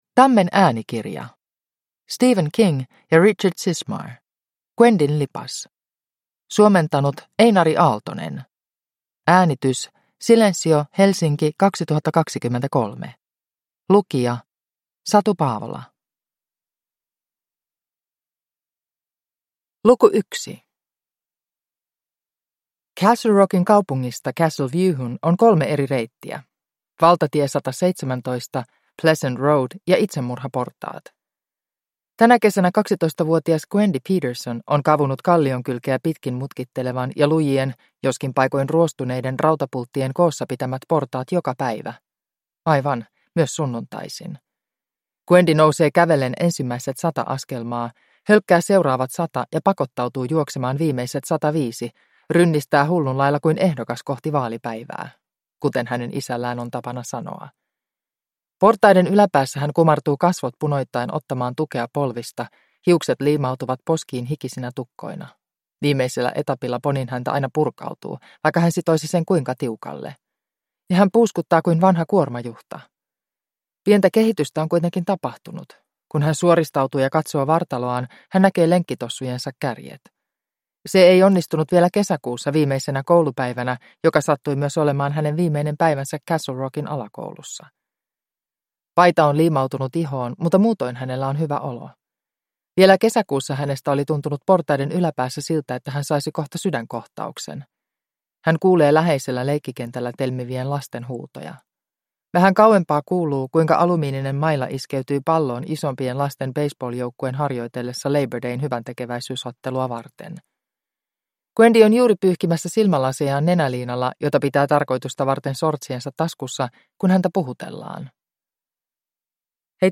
Gwendyn lipas – Ljudbok – Laddas ner